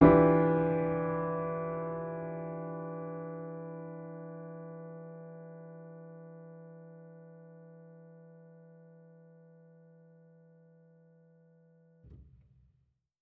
Index of /musicradar/jazz-keys-samples/Chord Hits/Acoustic Piano 2
JK_AcPiano2_Chord-Em7b9.wav